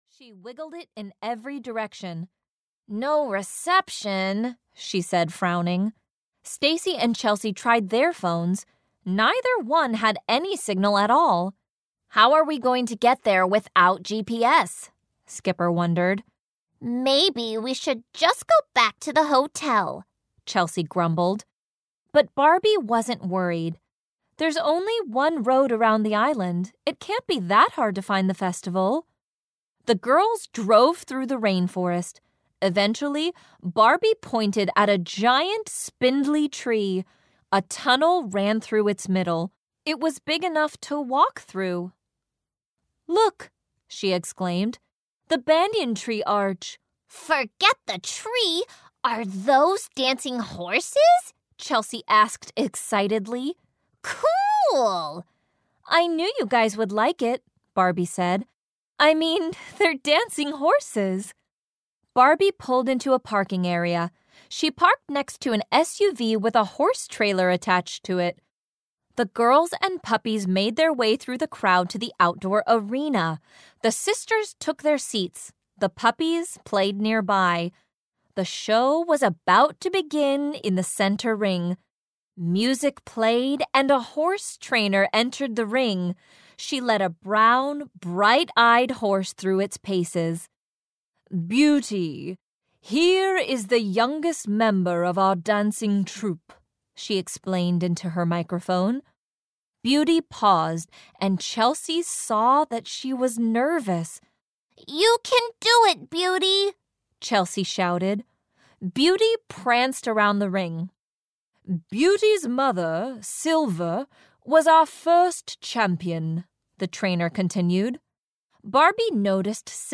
Audio knihaBarbie - Puppy Chase (EN)
Ukázka z knihy